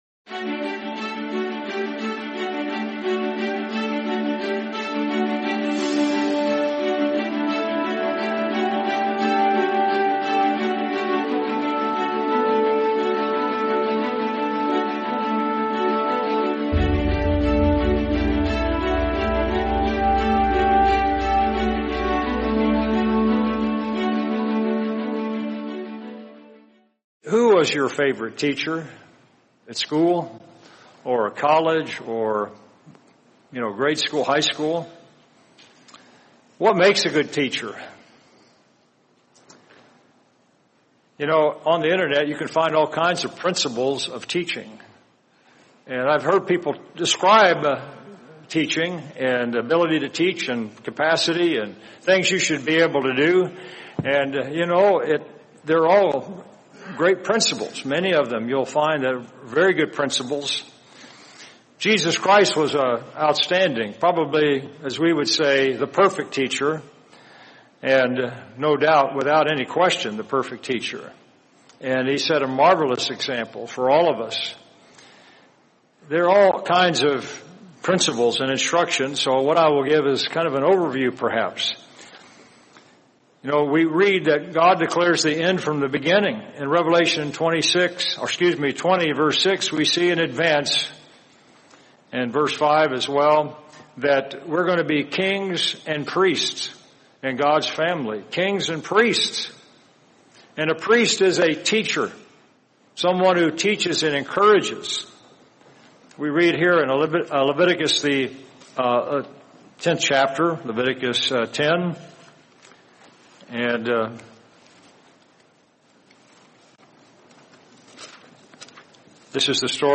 Feast of Tabernacles 2025: We're All in Training to Teach | Sermon | LCG Members